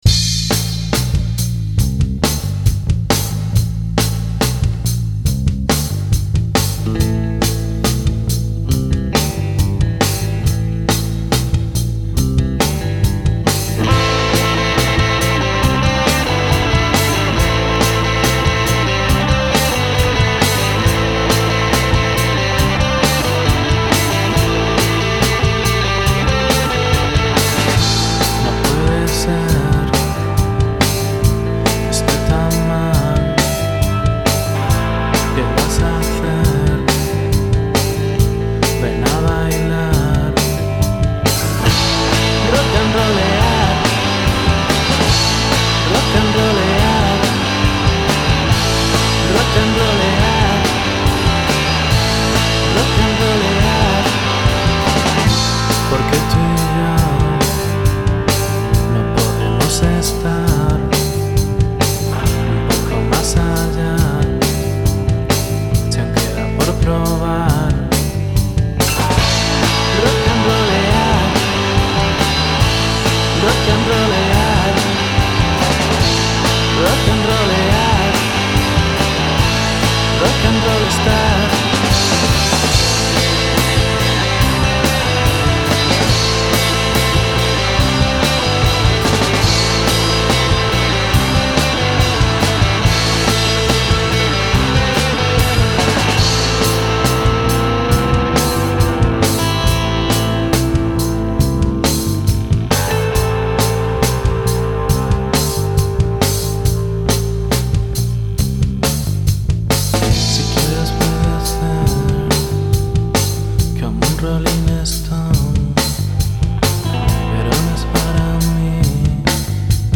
Estilo: Indie rock